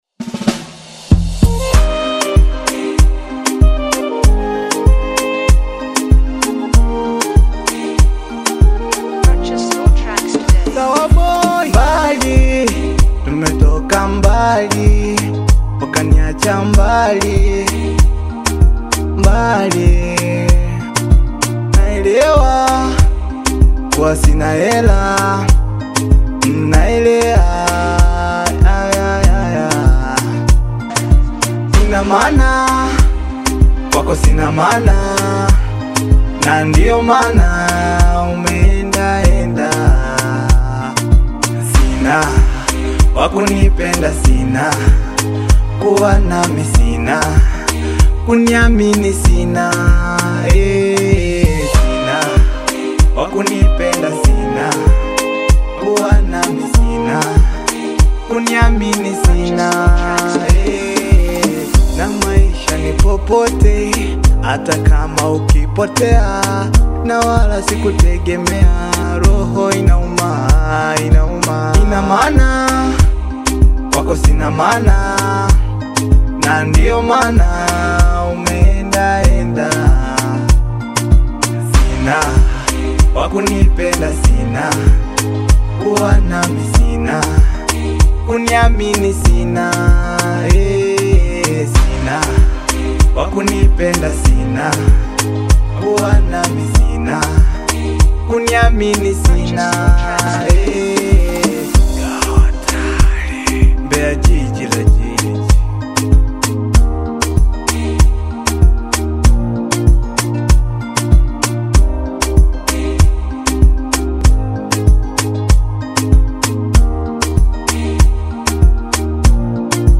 AudioBongo Flava